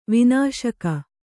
♪ vināśaka